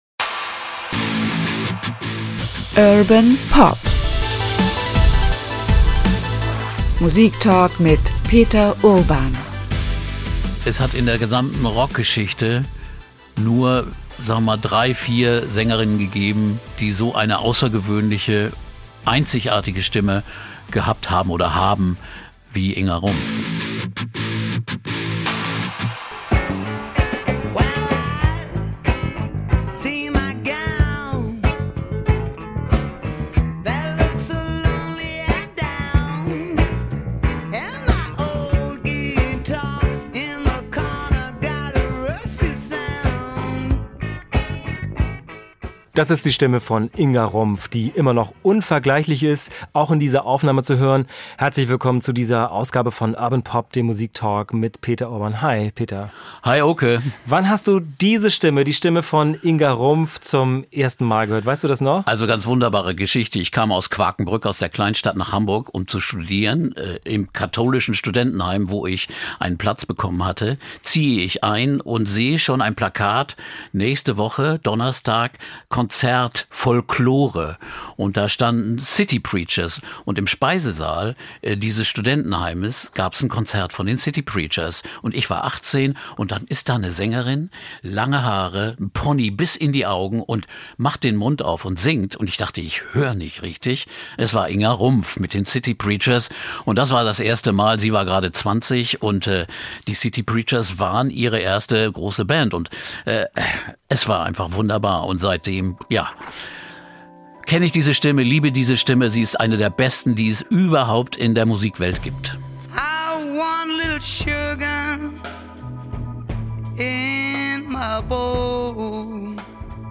Ihre kehlige, leicht raue Stimme steigt in die Höhe und klingt leidenschaftlich und stark. In den späten 60er Jahren schrieb sie mit den Hamburger „City Preachers“ deutsche Musikgeschichte in einer Band, deren Folkrockmusik kaum Grenzen kannte.